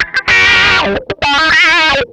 MANIC WAH 1.wav